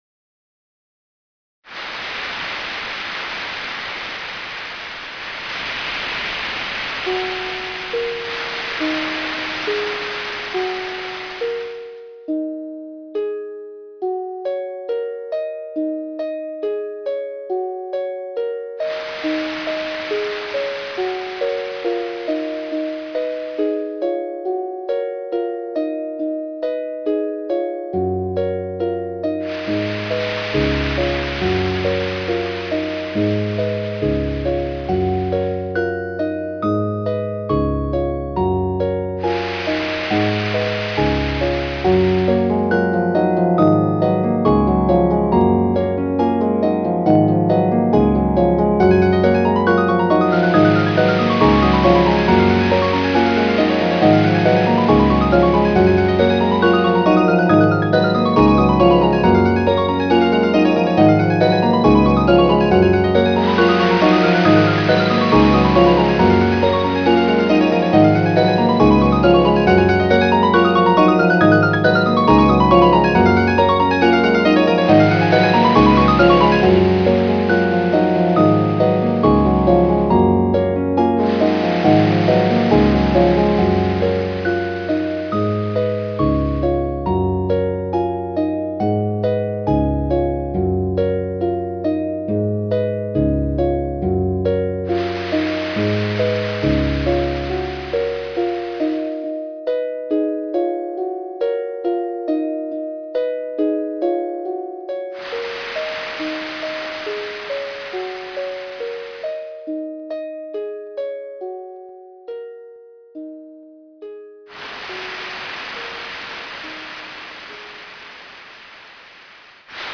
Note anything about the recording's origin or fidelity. I changed it to create stereo effects. It will sound much better with headphones.